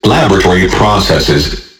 完美适用于打造震撼的低音、丰富的节奏纹理和地下音乐氛围。